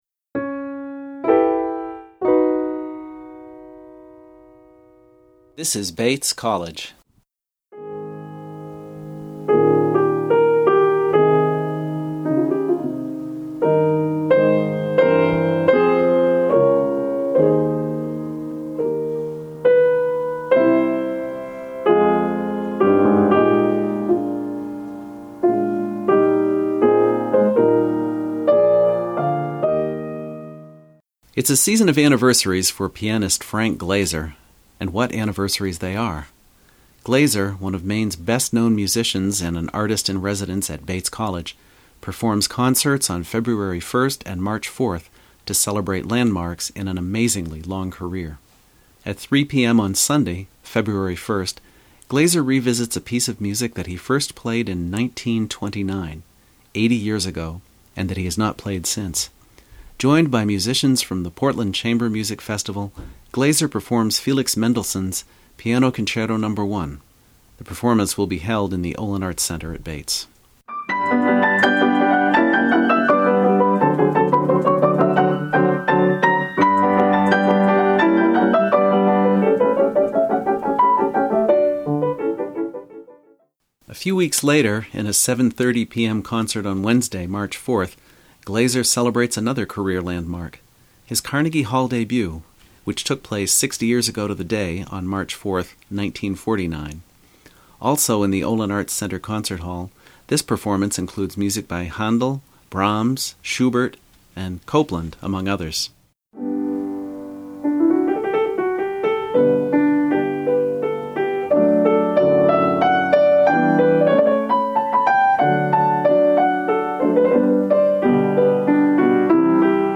Frank Glazer: 2009 anniversary concerts